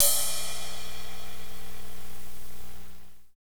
Index of /90_sSampleCDs/Northstar - Drumscapes Roland/CYM_Cymbals 3/CYM_P_C Cyms x